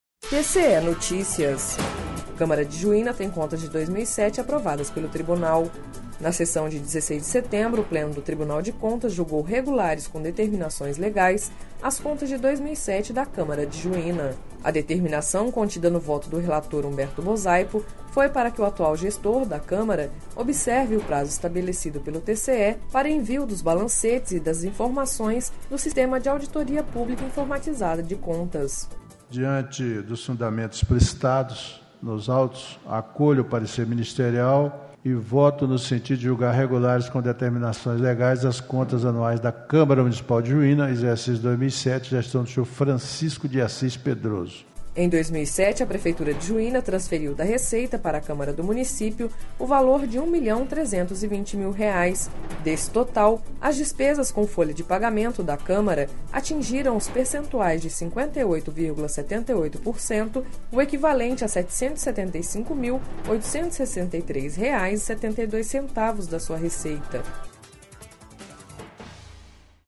Sonora: Humberto Bosaipo - conselheiro do TCE-MT